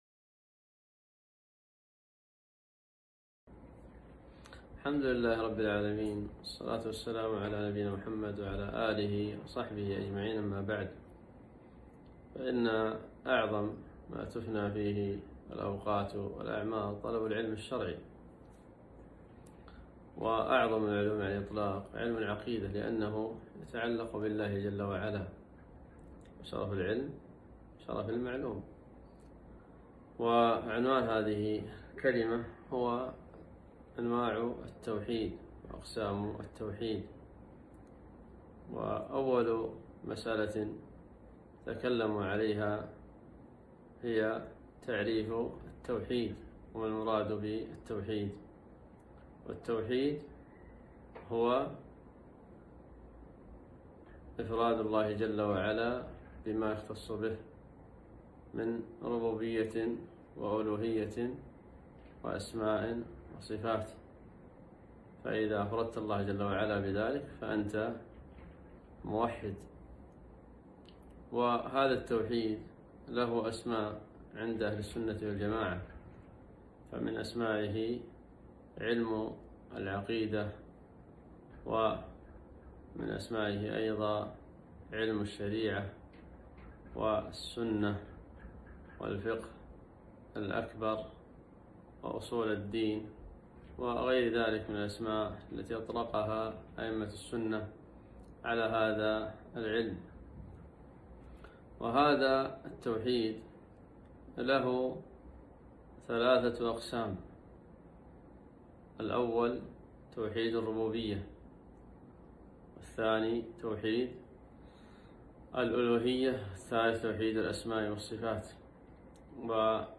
محاضرة - أنواع التوحيد الثلاثة وأدلتها